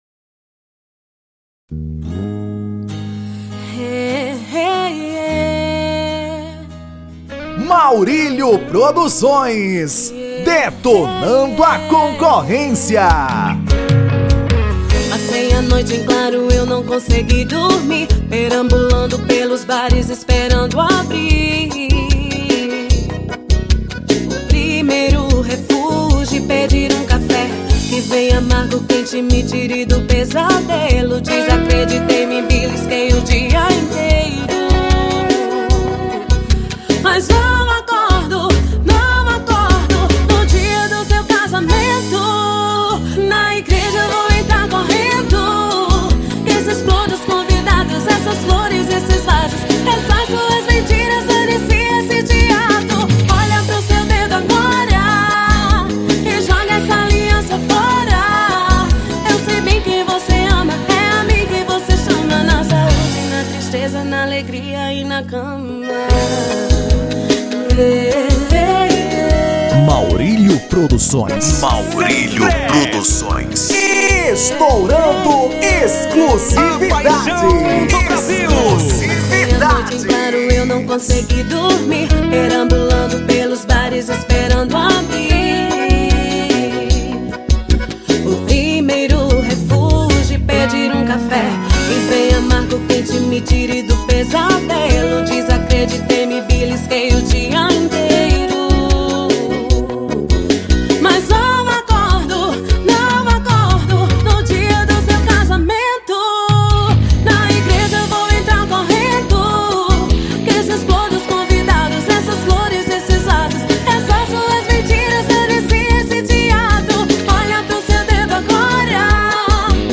Forró romântico